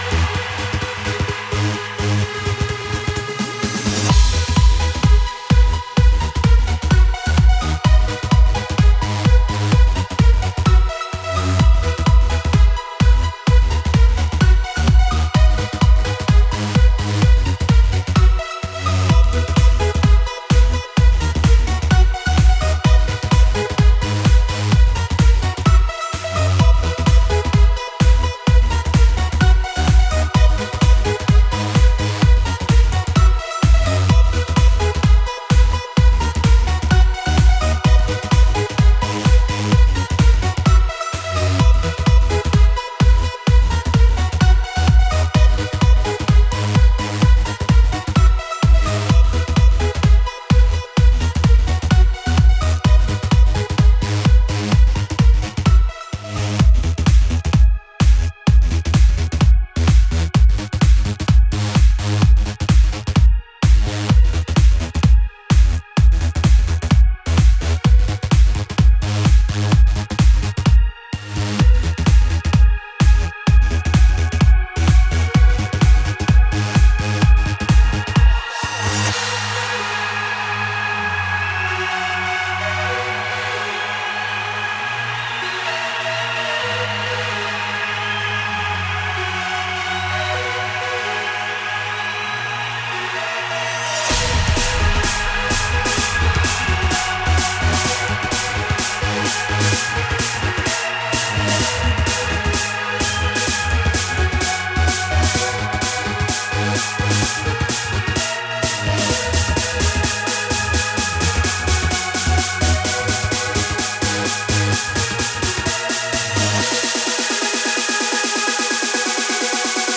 Жанр: Сlub